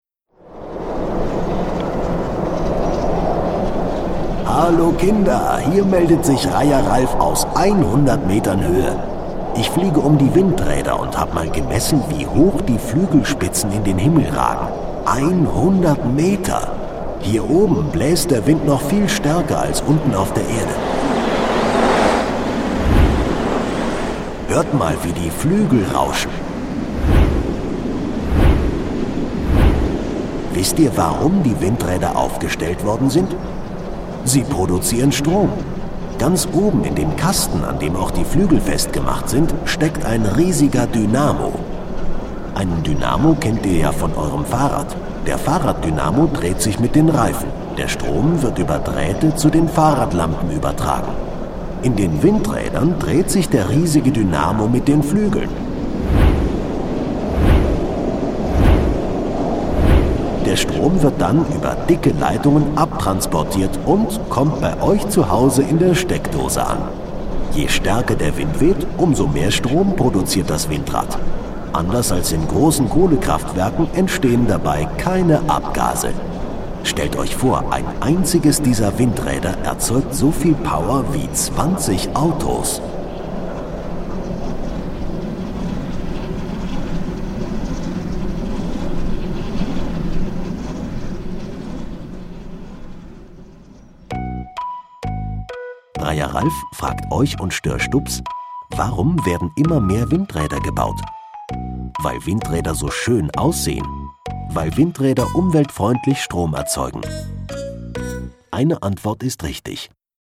Windpark Kranenburg - Kinder-Audio-Guide Oste-Natur-Navi